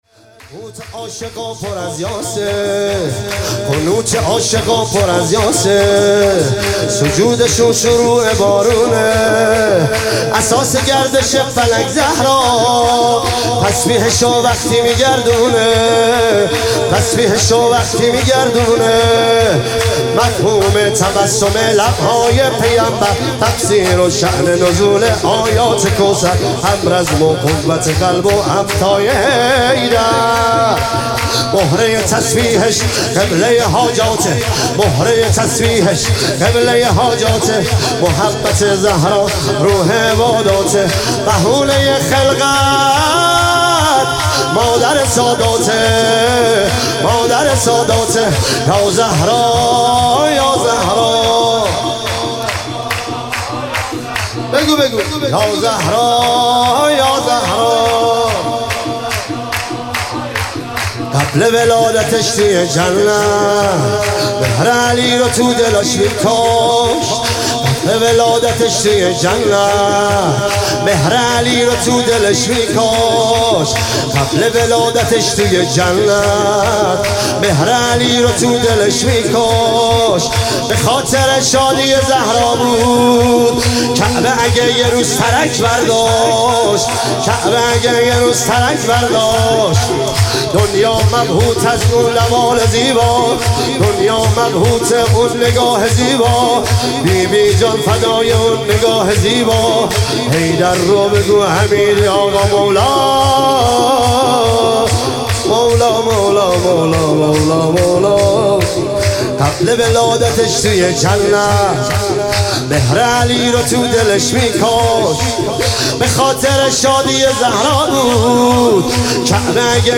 عنوان شب میلاد حضرت زهرا ۱۳۹۹ – بابل
سرود